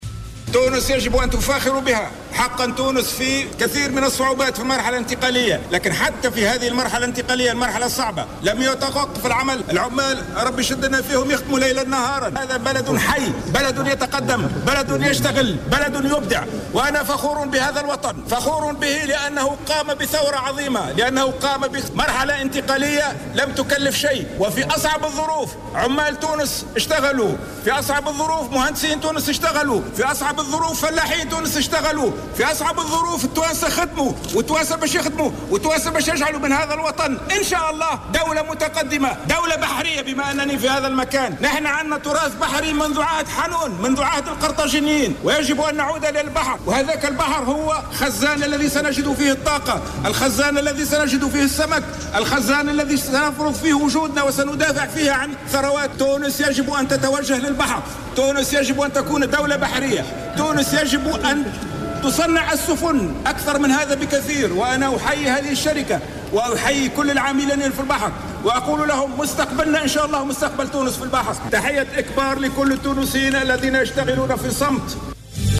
En marge de l'inauguration du prototype de vedette de surveillance maritime baptisé "l'Indépendance" à Sfax, le président provisoire de la République, Moncef Marzouki a appelé mercredi 7 mai 2014 les hommes d'affaires tunisiens à exploiter les richesses maritimes à investir dans ce secteur.